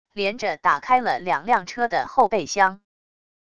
连着打开了两辆车的后备厢wav下载